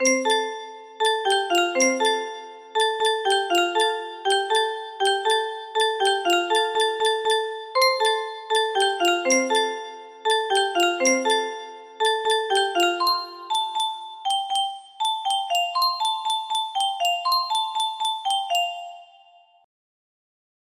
happy 21.23 music box melody